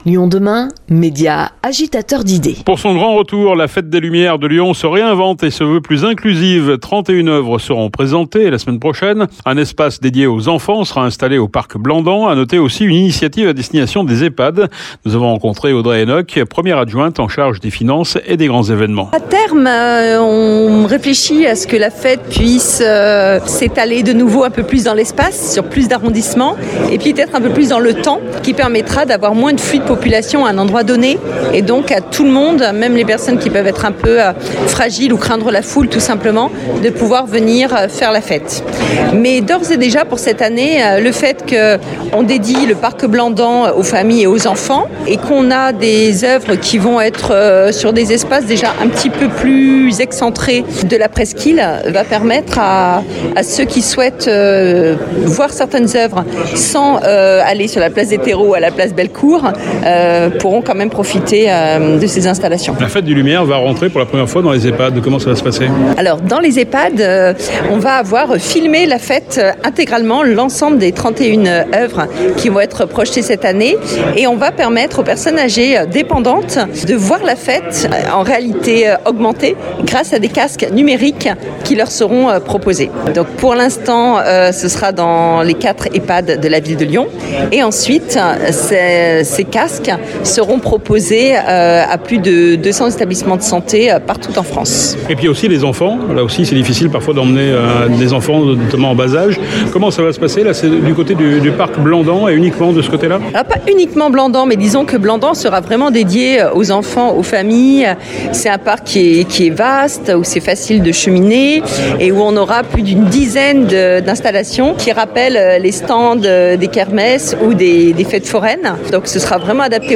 Nous avons rencontré Audrey Hénocque, première adjointe en charge des Finances et des Grands Evénements.